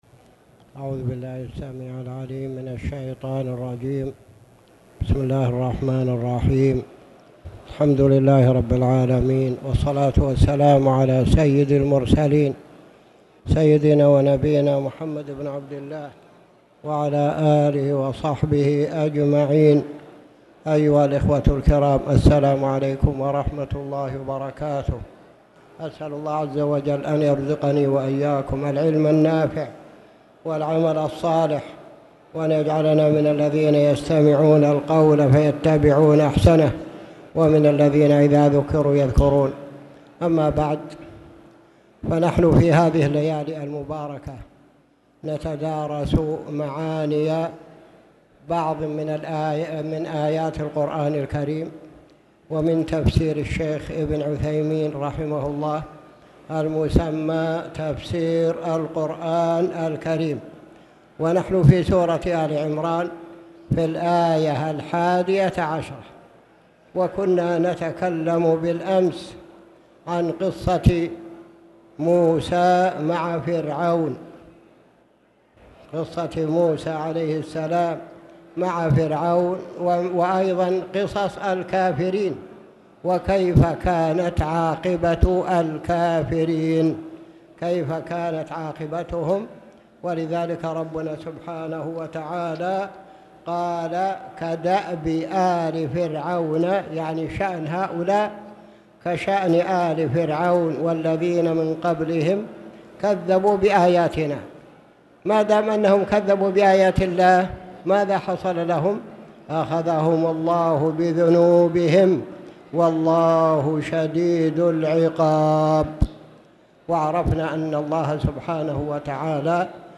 تاريخ النشر ١١ ربيع الثاني ١٤٣٨ هـ المكان: المسجد الحرام الشيخ